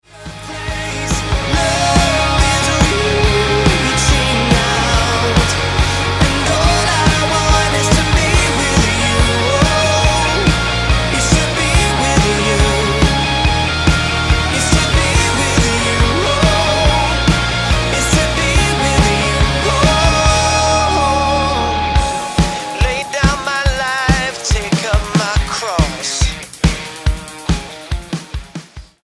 Style: MOR/Soft Pop Approach: Praise & Worship